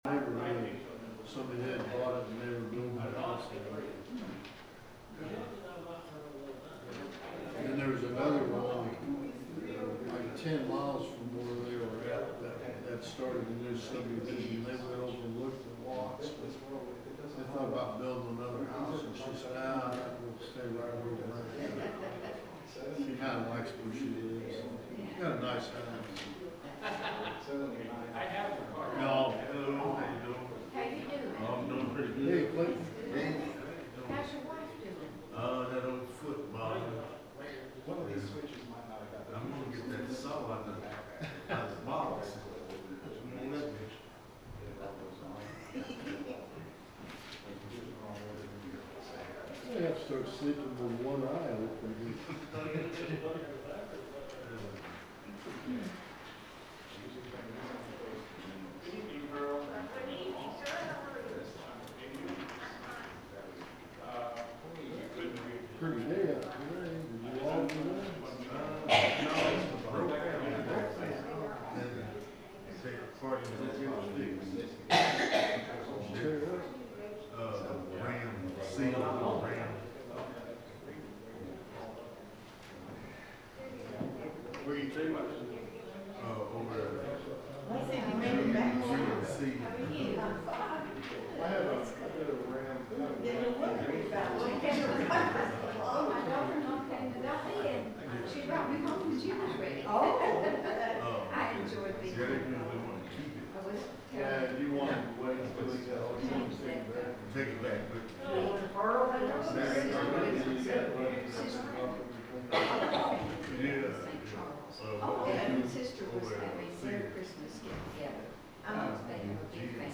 The sermon is from our live stream on 1/7/2026